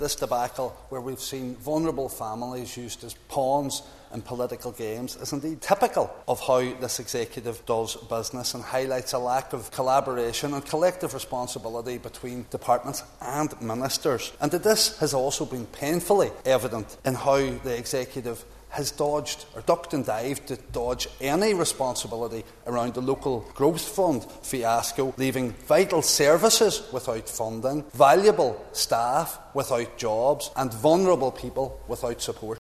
Foyle MLA, Mark H. Durkan, says the controversy shows how the Executive does business: